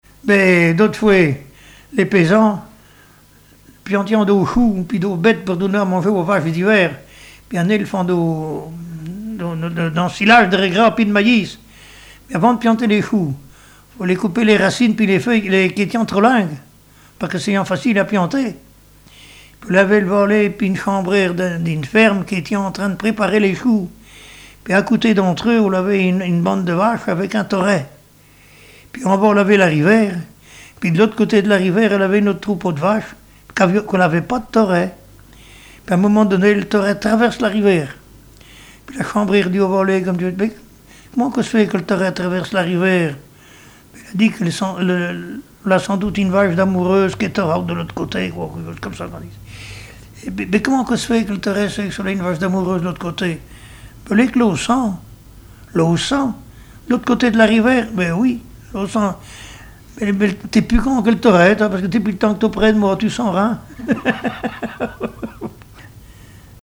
Langue Patois local
Genre sketch
Histoires drôles et chansons traditionnelles